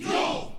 Yuaaa.wav